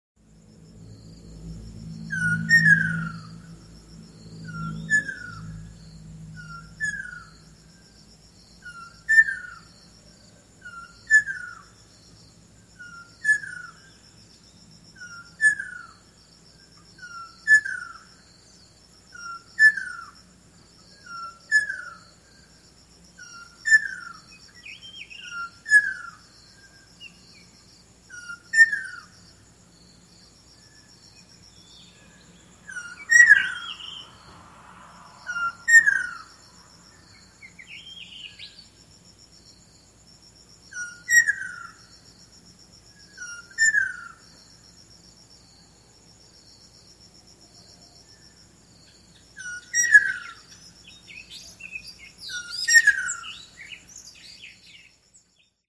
その睡眠を妨げるヤツが、朝５時４５分ごろやってきて、
クッカルルルル〜
わが家の庭先やら玄関のすぐ上を通る電線で鳴く。
アカショウビンが、９月になってまでパトロールするのは初めてのことで・・